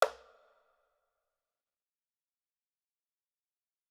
UI sound trad hover 3.wav